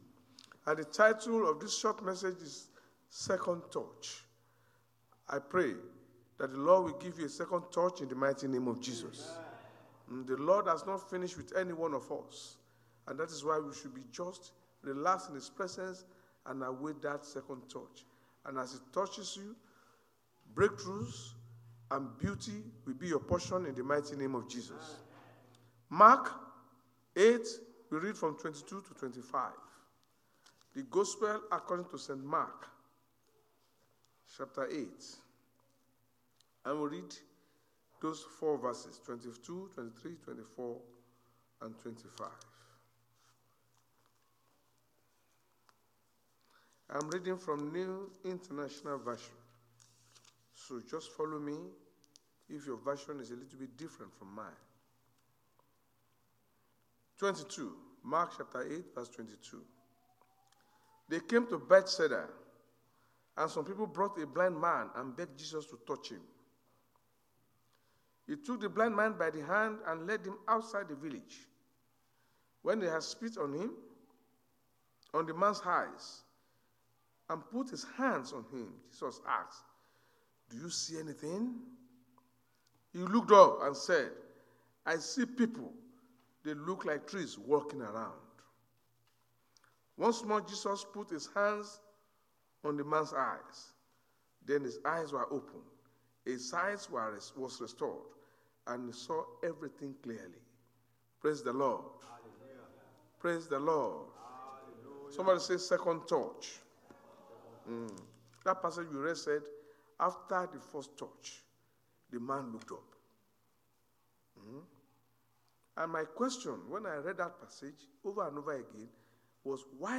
Service Type: Sunday Church Service